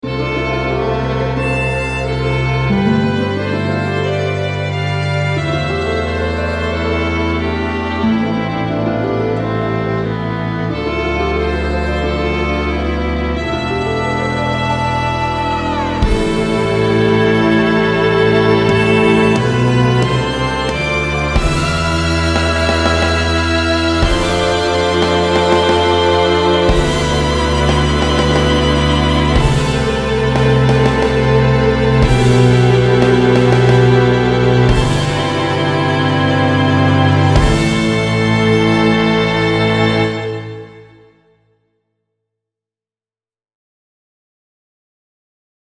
And for reference, I added a few more measures to the "Ode To A Node" piece, which includes two "cloned" LSO Solo Violins (panned left and right, respectively), as well as one Miloslav Philharmonic Solo Violin with tremolo but no specific tremolo marks, which is fabulous .
[NOTE: This is the MP3 (265-kbps [VBR], 1.5MB, approximately 46 seconds) that was created from the WAVE audio file exported from Notion 3 .